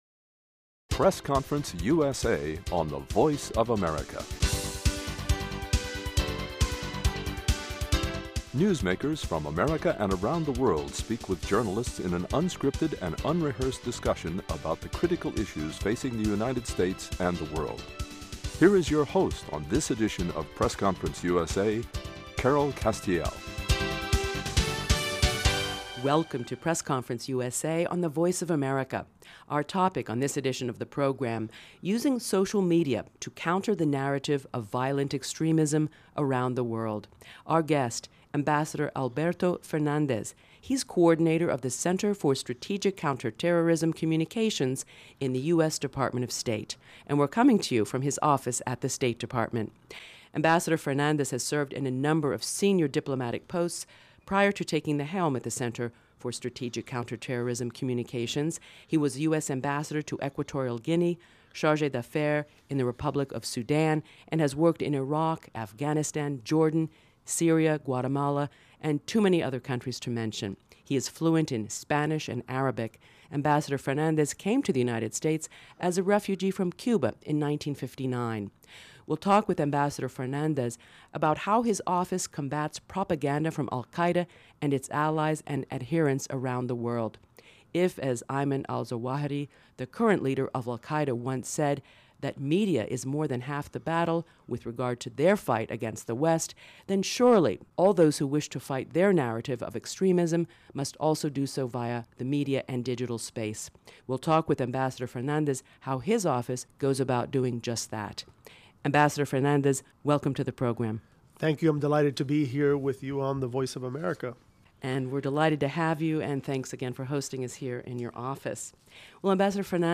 AMBASSADOR ALBERTO FERNANDEZ, COORDINATOR, CSCC A conversation with Ambassador Alberto Fernandez, Coordinator of the Center for Strategic Counterterrorism Communications at the US Department of State (CSCC), about how his office uses social media to combat al Qaida propaganda on the Internet.